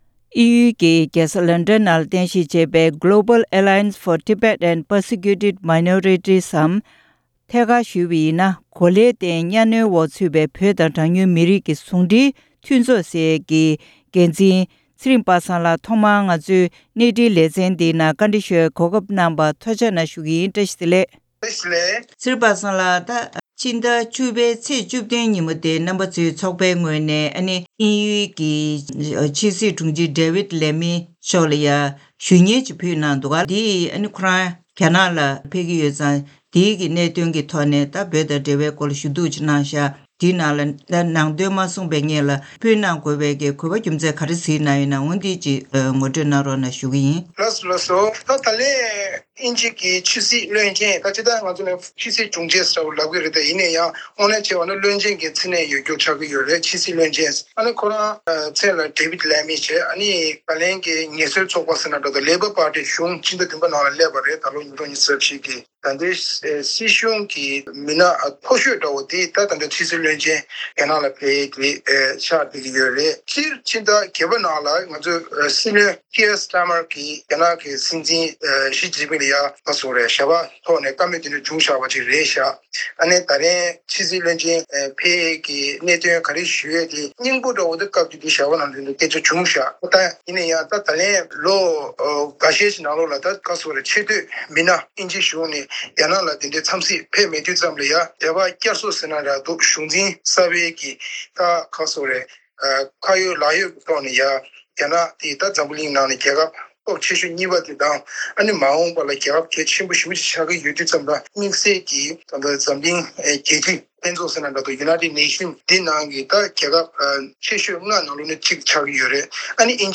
གནས་འདྲི་ཞུས་པ་ཞིག་གསན་གནང་གི་རེད་།